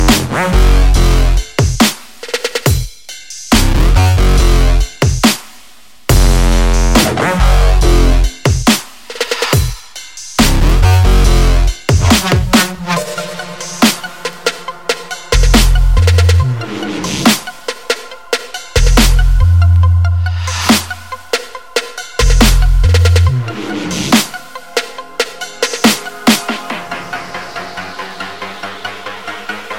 TOP >Vinyl >Grime/Dub-Step/HipHop/Juke
Instrumental